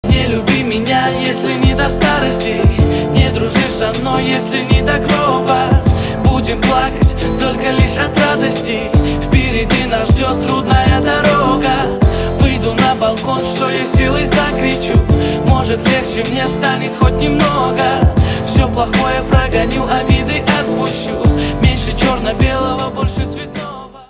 • Качество: 320, Stereo
лирика
Хип-хоп